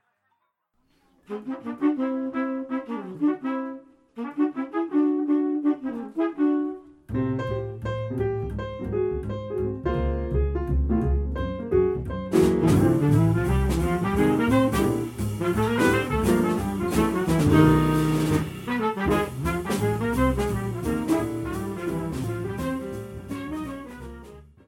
trumpet
sax
piano
bass
drums